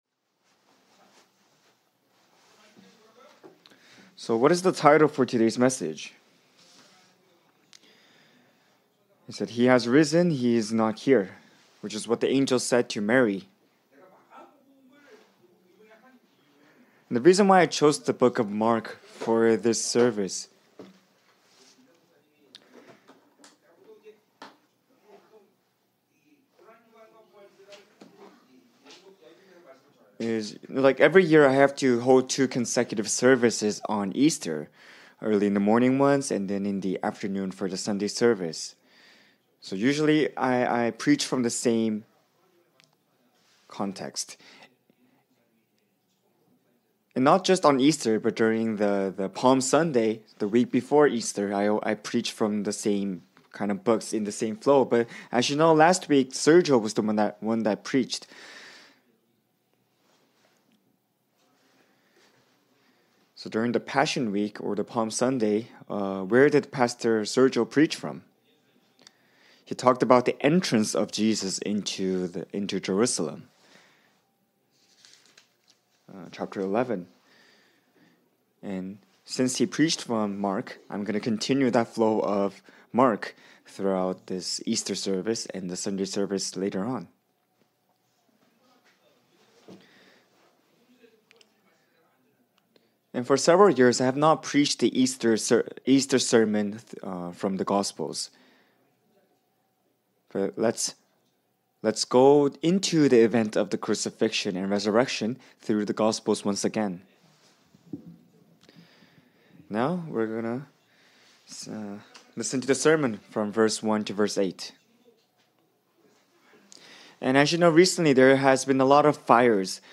[2025.Apr][Mark 16:1-8] He Has Risen; He Is Not Here - Zoe Ministry Sermons in English